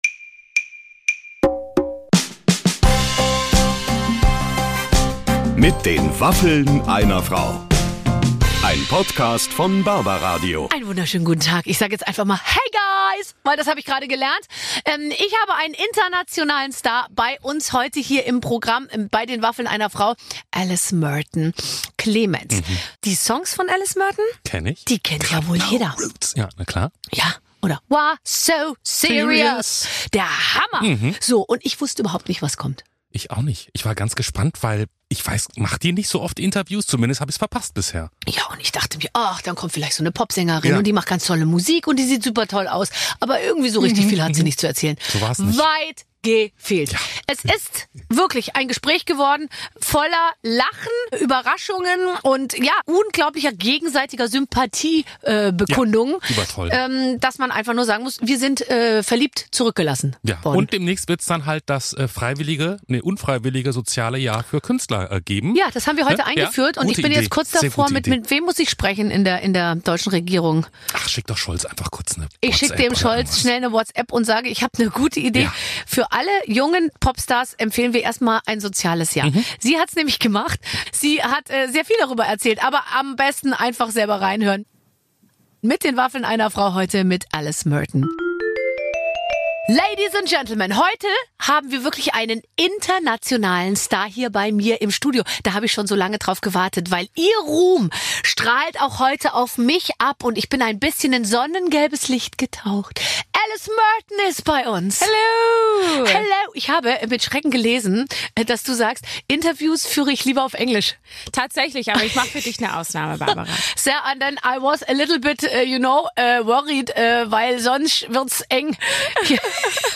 In dieser Podcast-Episode drücken Sarah Kuttner und Barbara Schöneberger ganz schön auf die Tube! Witz, Charme und Peinlichkeiten werden quasi doppelt so schnell ausgetauscht. Aber wir erfahren sooo viel über Sarah Kuttner: Zum Beispiel, dass sie ein großes Herz für Wochenschlüppis hat, oder welche merkwürdigen Komplimente sie nach der Hochzeit von Bekannten bekommen hat.